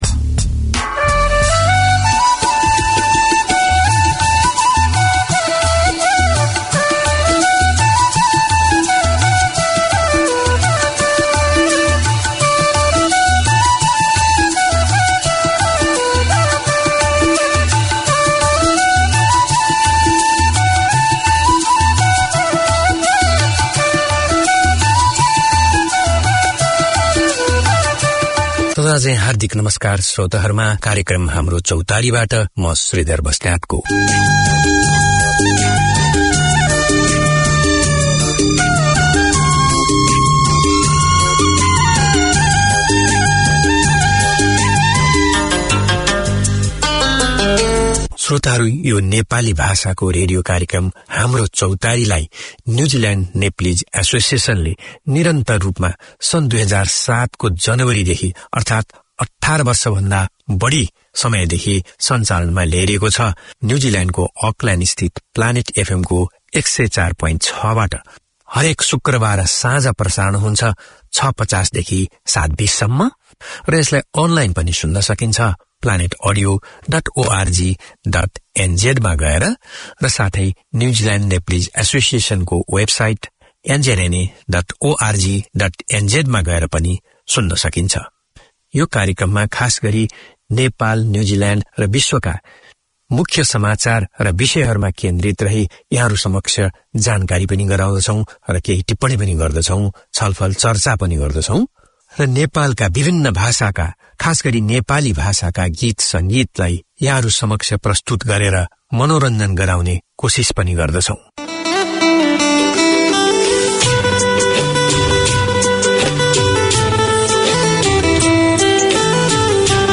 Each week Haamro Chautari offers the chance for the Nepalese community to gather round and share their culture, news of the local community as well as the latest from Nepal. The hosts also present a selection of new music and golden hits. Interviews and updates on community events in Auckland keep the connection with the Nepalese way of life.